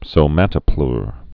(sō-mătə-plr, sōmə-tə-)